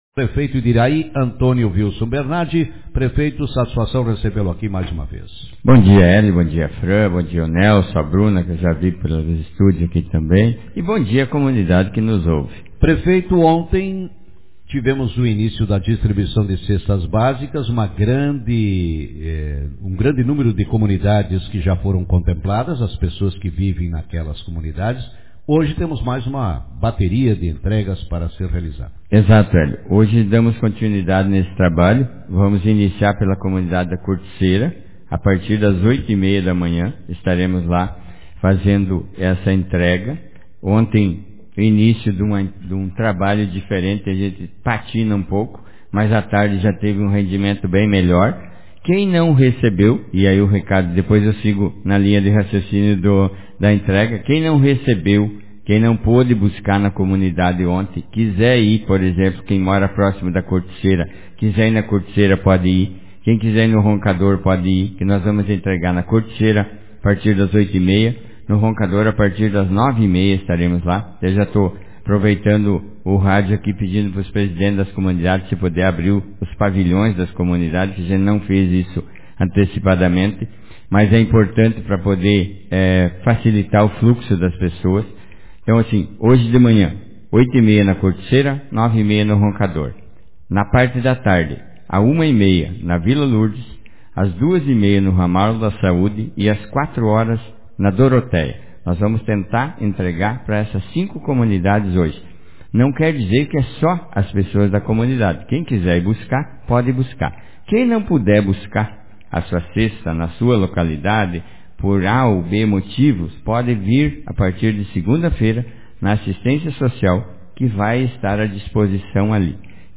Cestas básicas seguem sendo distribuídas no interior de Iraí Autor: Rádio Marabá 12/05/2022 Manchete Na manhã desta quinta-feira, 12, o prefeito de Iraí, Antonio Vilson Bernardi, participou do programa Café com Notícias e na ocasião, falou sobre a entrega de cestas básicas para famílias residentes no interior do município.